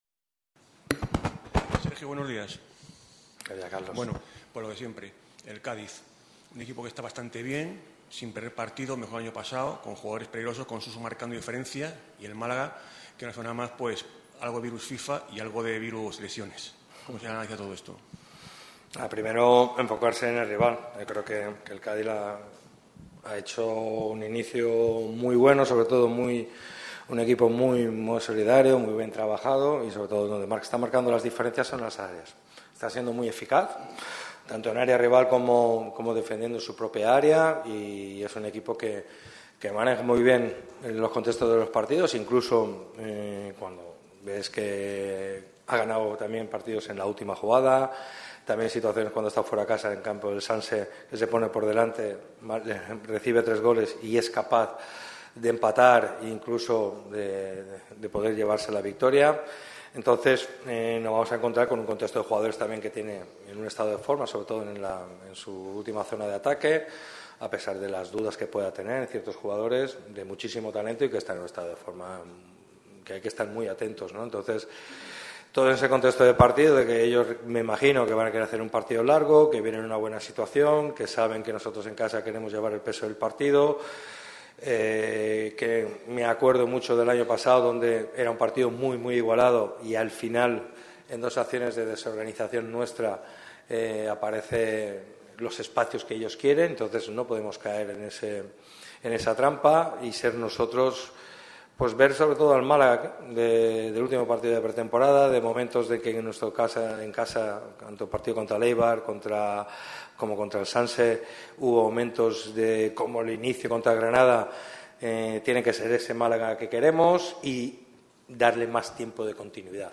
El técnico de Nules ha comparecido ante los medios en la previa del enfrentamiento que medirá al Málaga CF contra el Cádiz CF el próximo domingo a las 18:30 horas. El tema más importante a tratar, desgraciadamente, fueron las lesiones.